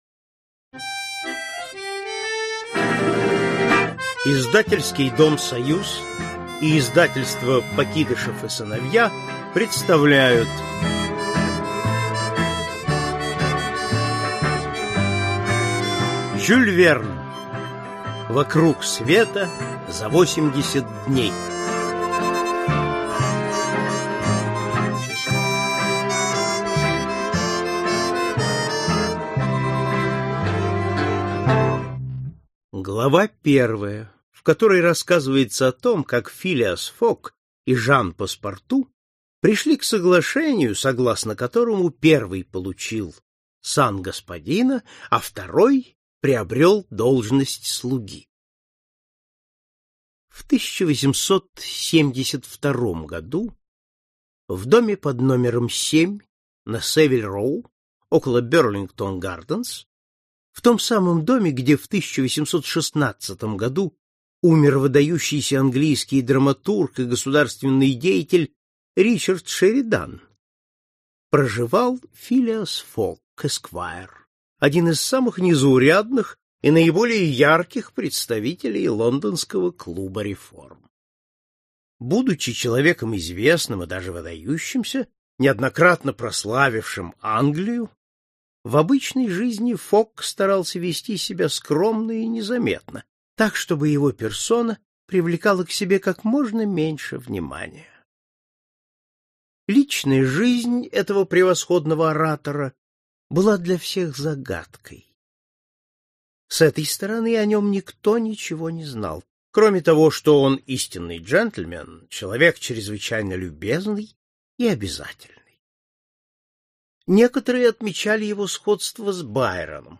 Аудиокнига 80 дней вокруг света | Библиотека аудиокниг